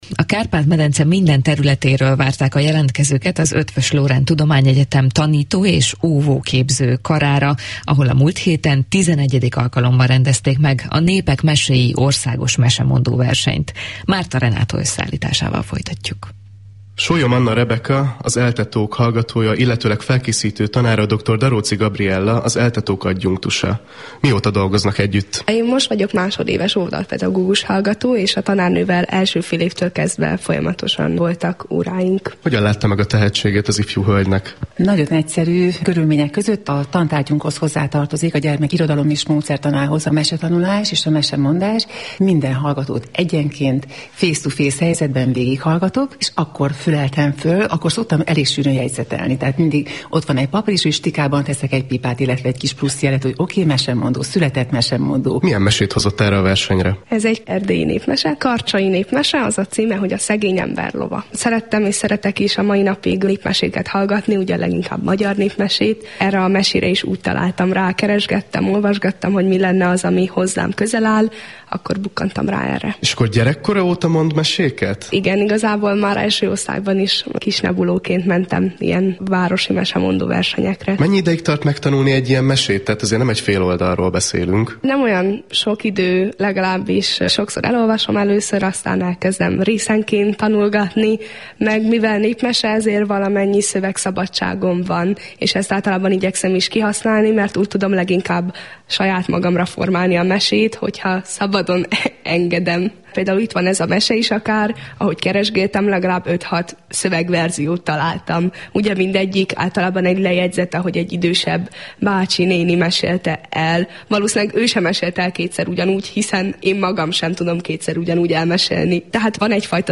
Rádiós beszélgetés a mesemondó versenyről
A XI. Népek meséi Országos Egyetemi és Főiskolai Mesemondó Versenyről a Kossuth rádió készített anyagot, ami március 26-án 10:34-kor ment le a Napközben c. adásban.
Rádiós beszélgetés